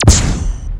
fire_laser5.wav